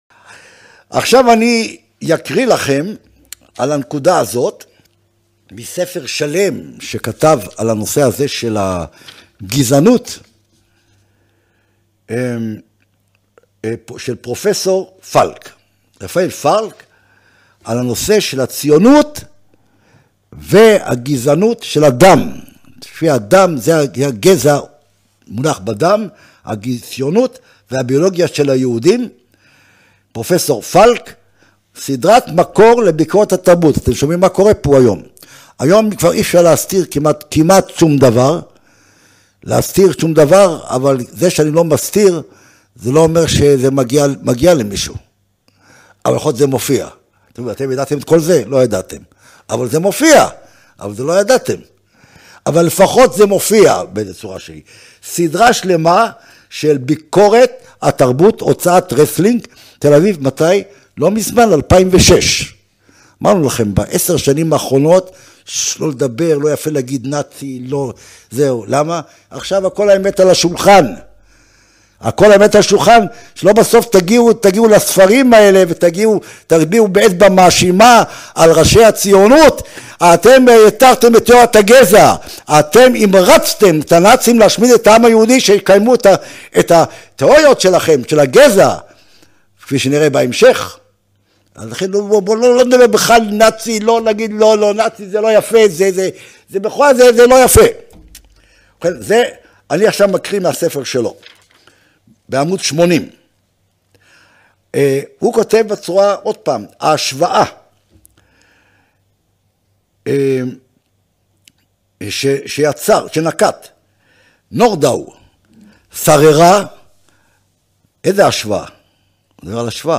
הציונות: גילויים וחשיפות – הרצאה 3 מתוך 4